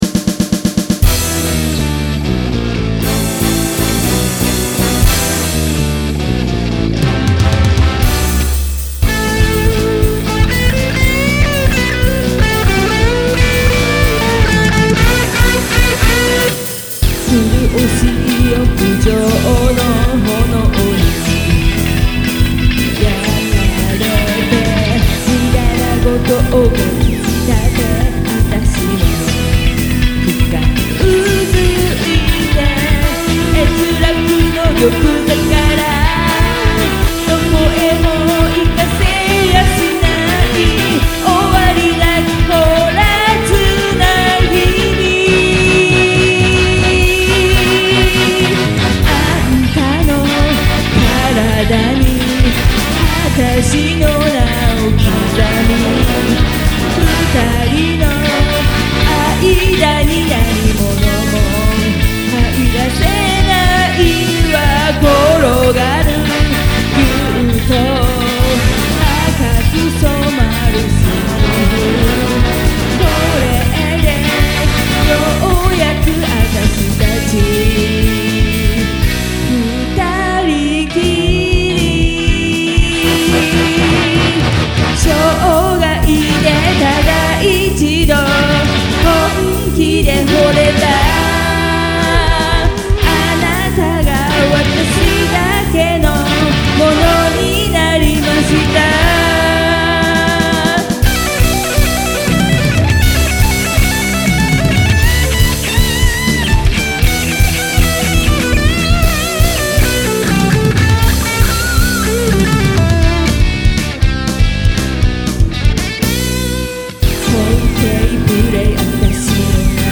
オリジナル曲を作成したので、料理ブログなのでアレなんだけど^^; ＵＰしてみます。
歌はボーカリストでもない私が歌っているので、お聴き苦しいかと思いますが、ご了承くださいませ<(_ _)>